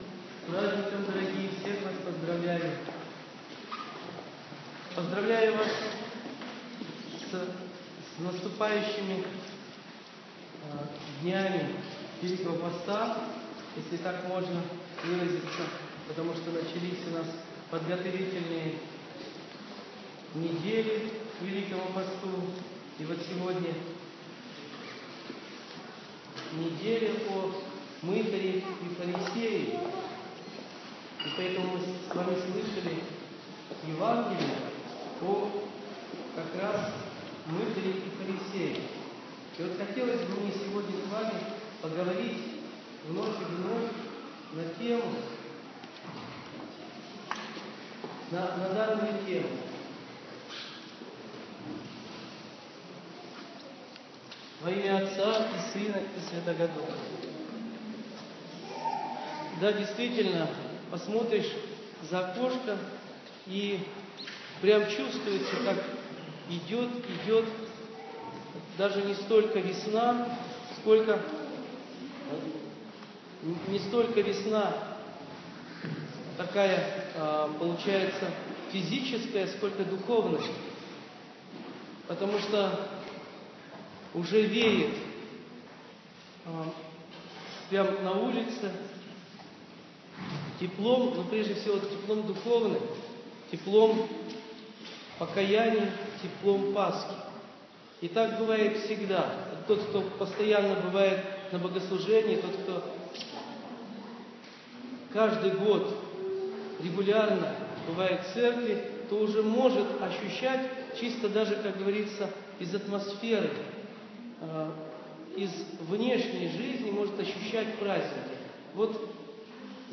Слово
после Литургии 21.02.16 об исправлении своей жизни и покаянии